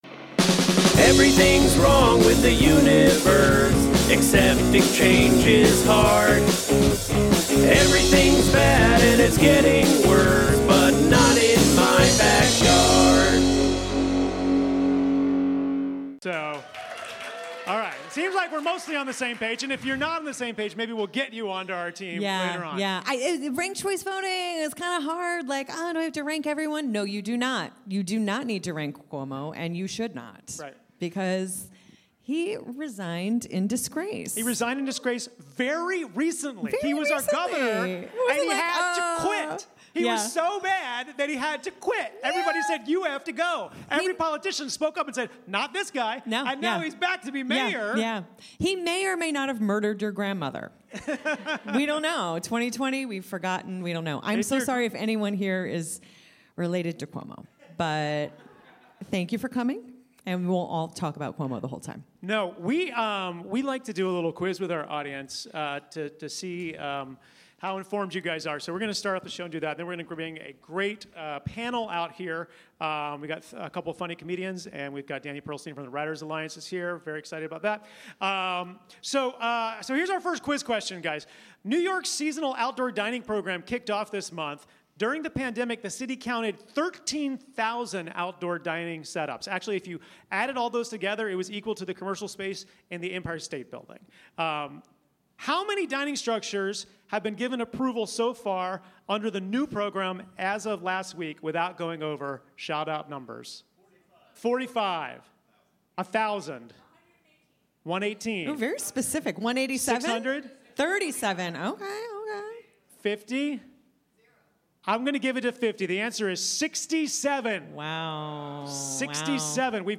Live form the UCB Theatre NY, we discuss the new subway map, and unfortunate incident on the R train, horse poop, and composting.